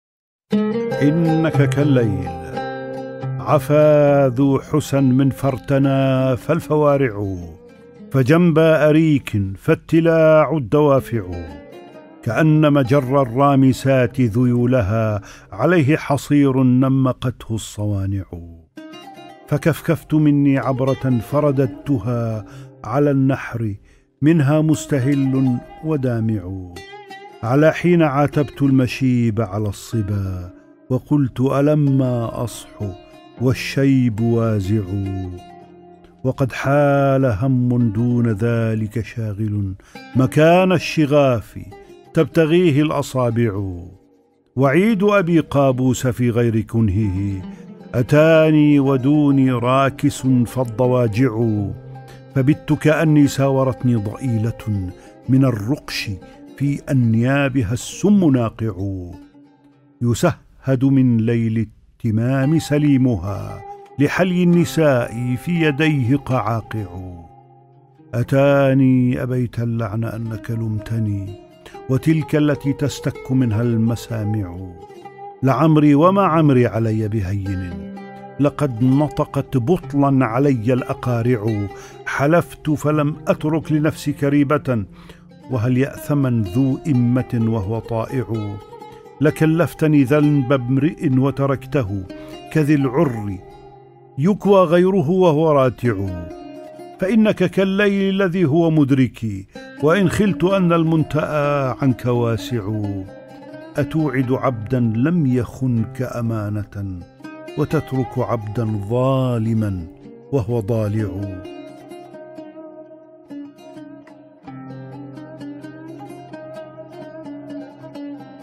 مجلدات الشعر / مختارات من ديوان النَّابِغة الذُّبْيانِيّ - قراءة عارف حجاوي